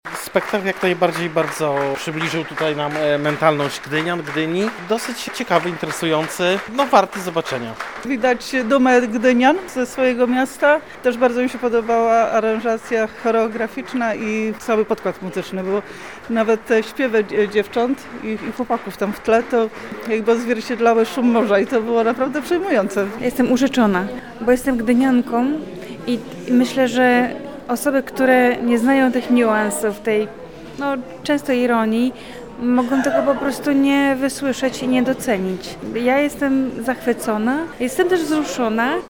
Jak mówili po wyjściu z teatru – było warto. Historia bawiła, zaskakiwała, ale też wzruszała.